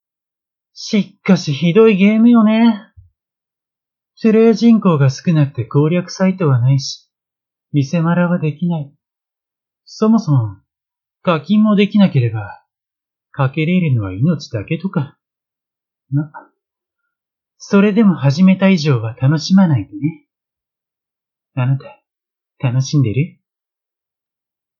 占い師を生業とする男性（オネエ口調）
SampleVoice01